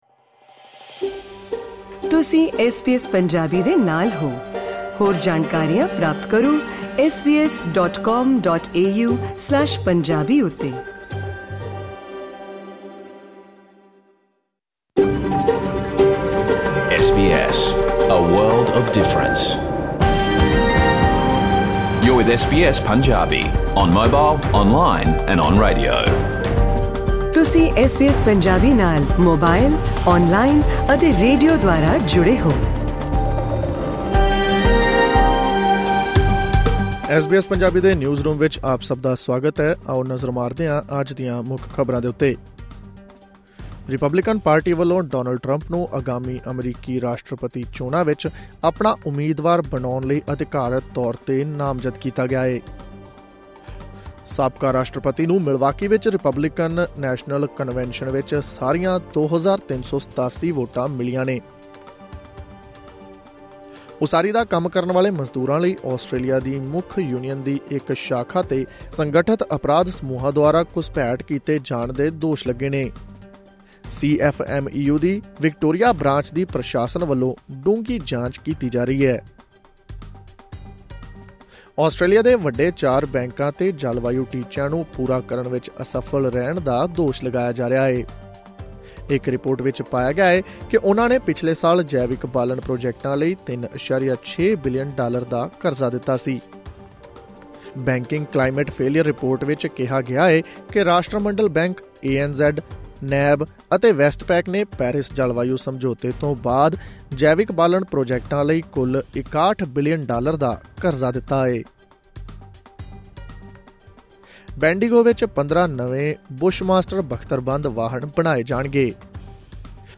ਐਸ ਬੀ ਐਸ ਪੰਜਾਬੀ ਤੋਂ ਆਸਟ੍ਰੇਲੀਆ ਦੀਆਂ ਮੁੱਖ ਖ਼ਬਰਾਂ: 16 ਜੁਲਾਈ 2024